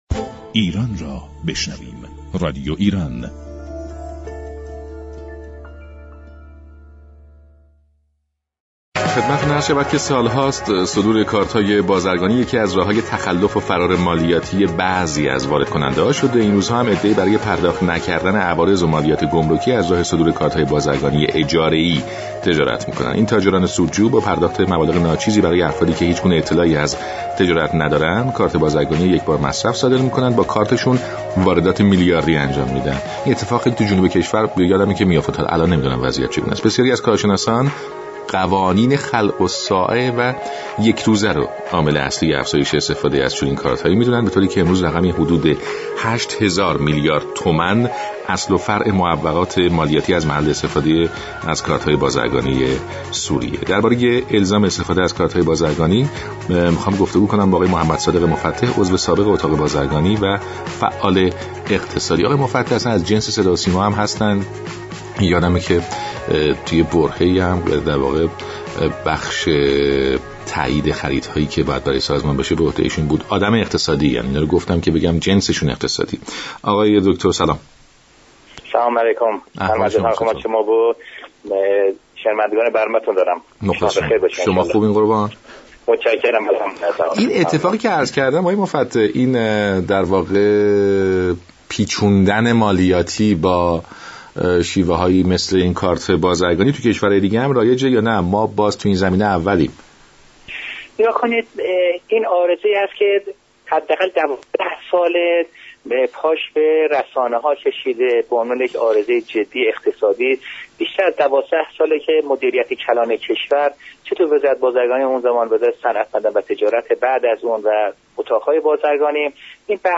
عضو سابق اتاق بازرگانی در گفت و گو با رادیو ایران گفت: از آنجا كه علاوه بر ایران بسیاری از كشورهای نیز با این پدیده روبرو هستند بهتر است برای از بین بردن و ریشه كن كردن كامل آن از الگوی دیگر كشورها استفاده كنیم.